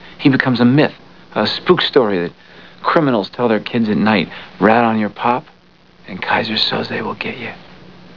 . . . FROM THE MOVIE "The Usual Suspects" . . .